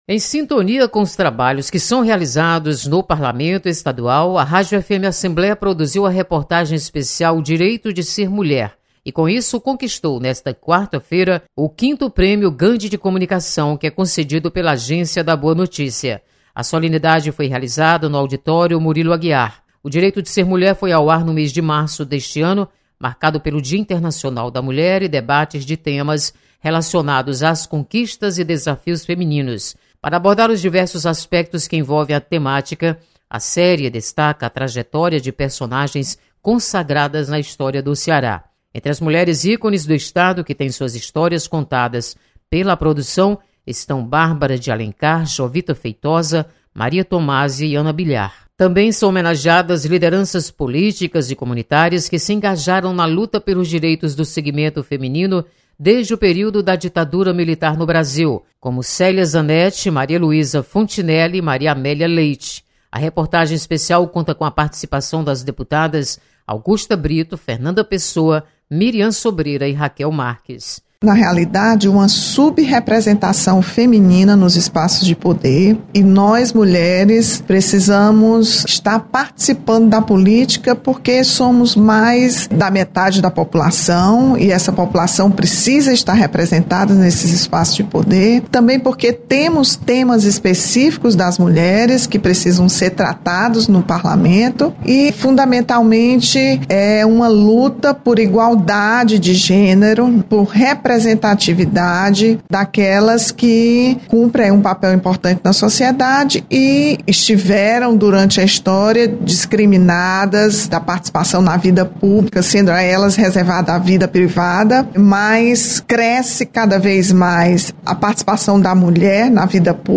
Rádio FM Assembleia é ganhadora do Prêmio Gandhi de Jornalismo 2018. Repórter